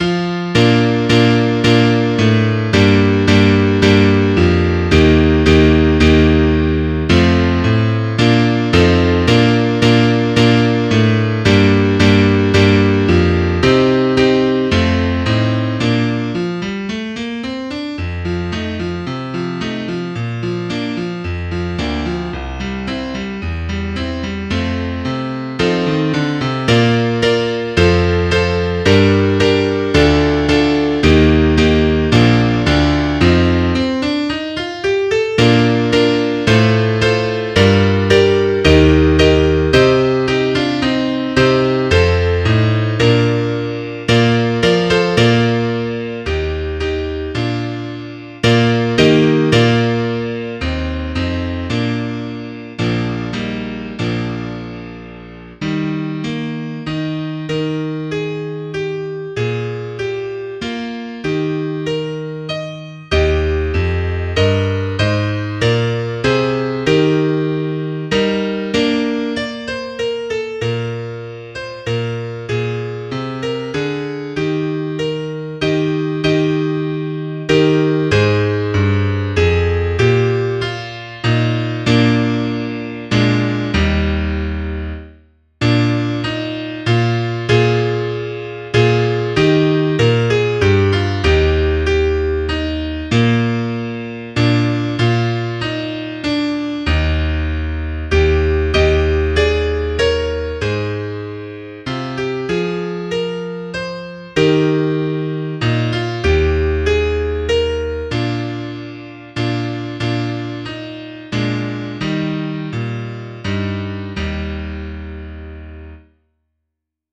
(Klavierstimme ohne Solo)